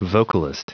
Prononciation du mot vocalist en anglais (fichier audio)
Prononciation du mot : vocalist